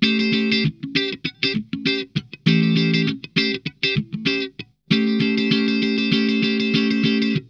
DEEP CHUG 4.wav